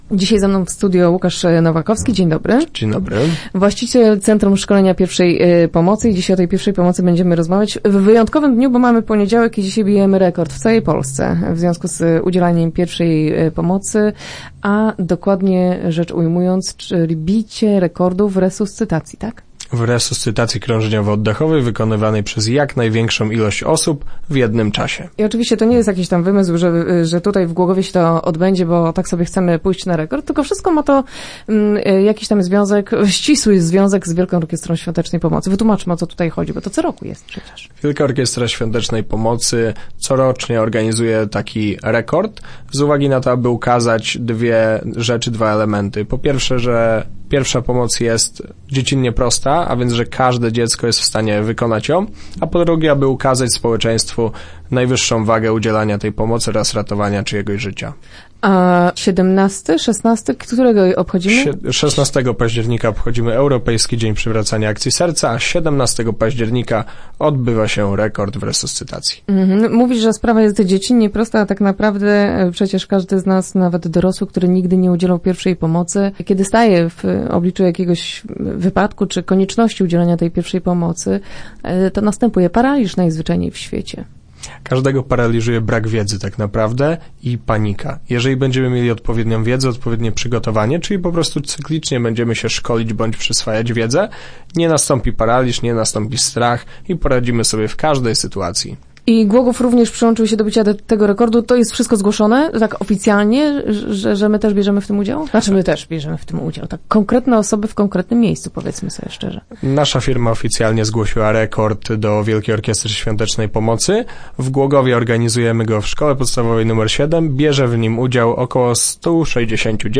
Start arrow Rozmowy Elki arrow Ustanowią Rekord Guinnessa?